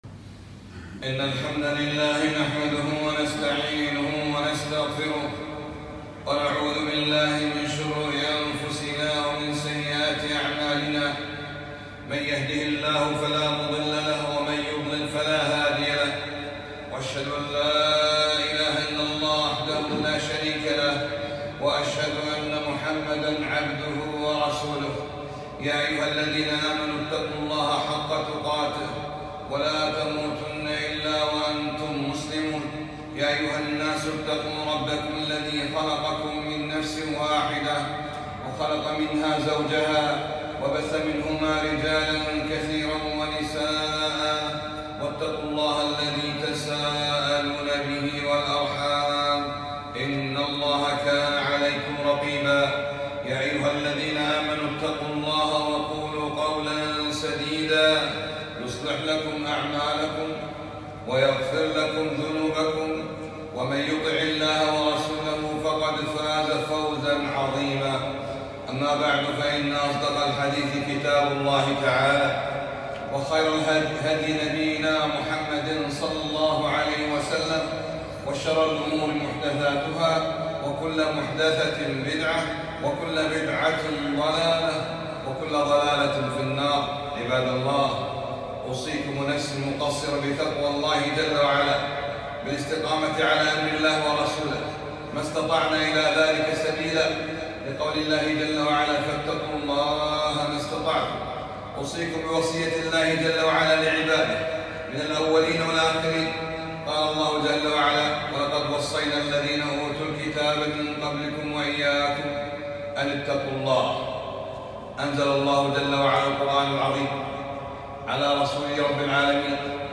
خطبة - {قُلِ الْحَمْدُ لِلَّهِ}